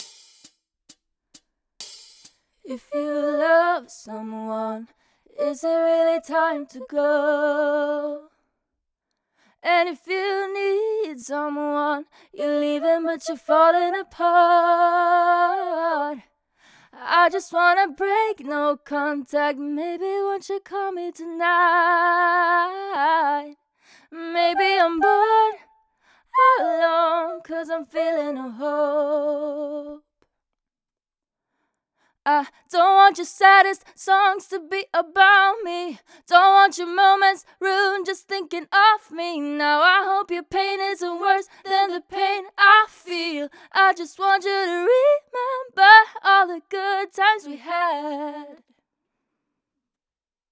2. Förberedd uppgift – Topline